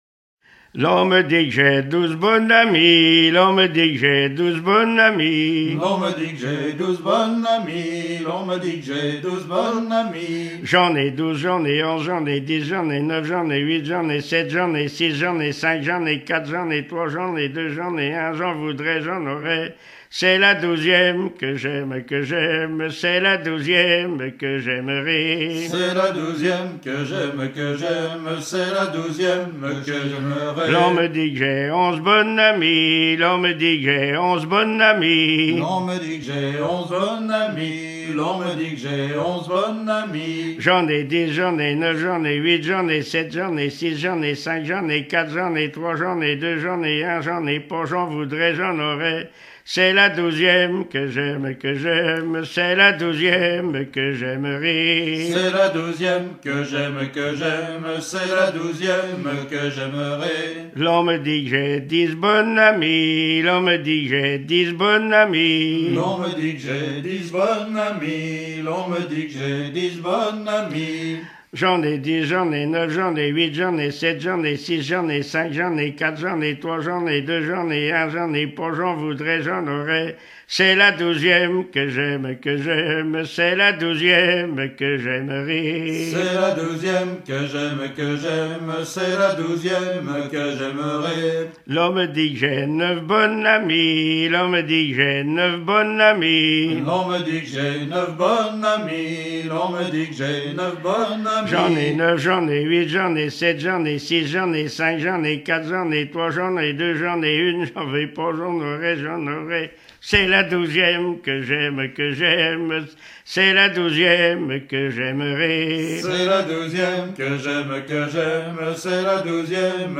Saint-Martin-des-Noyers
Genre énumérative